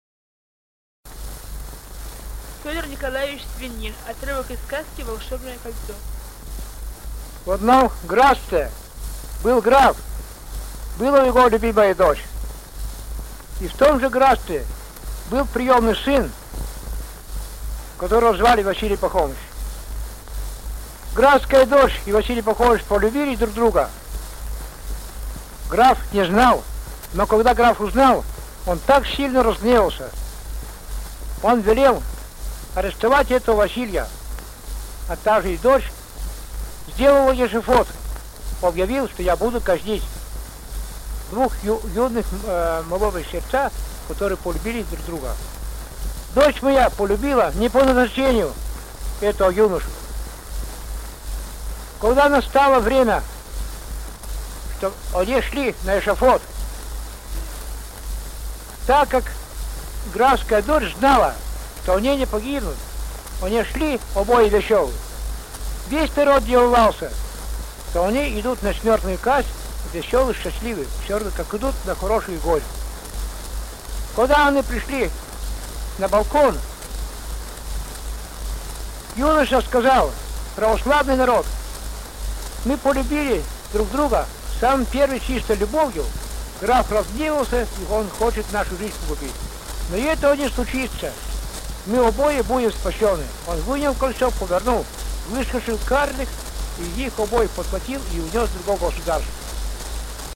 Фрагмент сказки